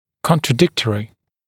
[ˌkɔntrə’dɪktərɪ][ˌконтрэ’диктэри]противоречивый, противоречащий
contradictory.mp3